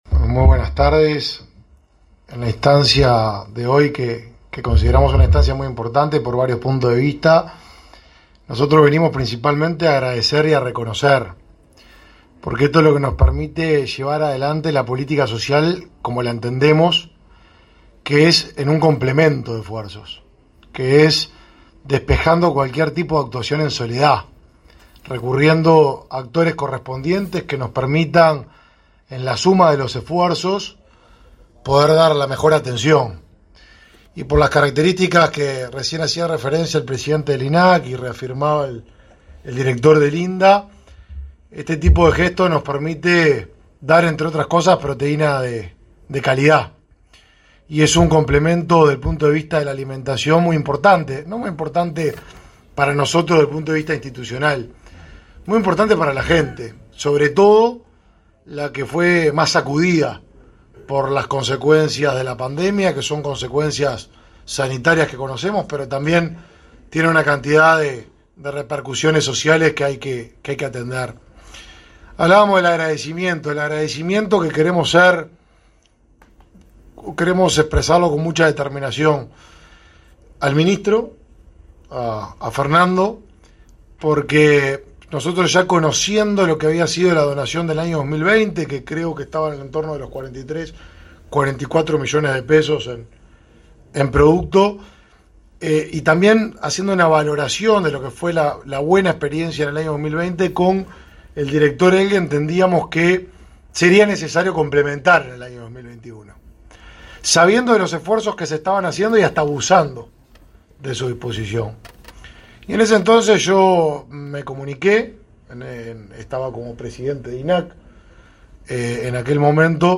Palabras de los ministros de Desarrollo Social y Ganadería
El ministro de Desarrollo Social, Martín Lema, y su par de Ganadería, Fernando Mattos, informaron a la prensa acerca del abastecimiento de carne por